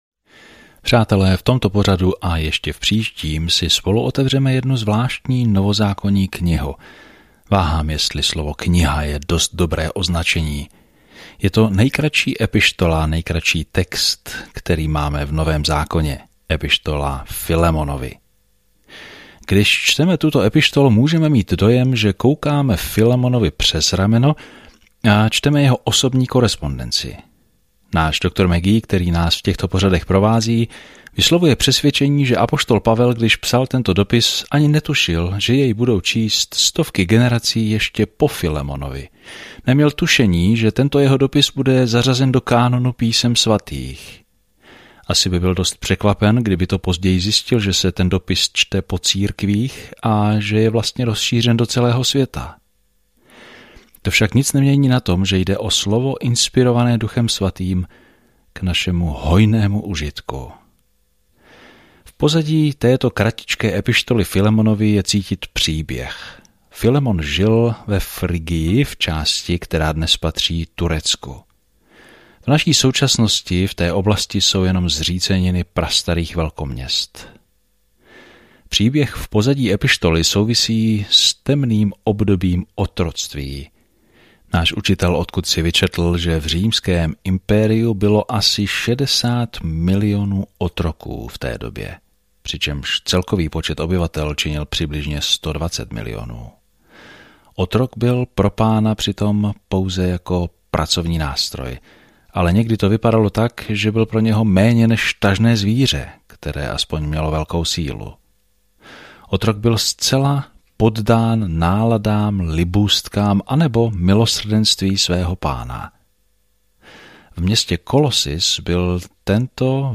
Písmo Filemon 1:1-9 Začít tento plán Den 2 O tomto plánu Evangelium mění životy – stejně jako tento krátký dopis vybízí bratra, aby přijal zpět dalšího bratra. Denně procházejte Filemonem a poslouchejte audiostudii a čtěte vybrané verše z Božího slova.